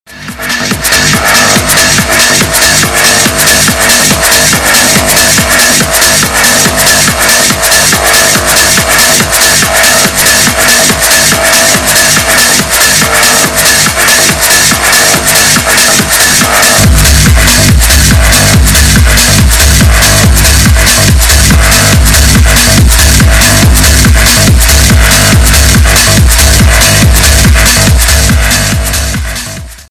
Techno extract 1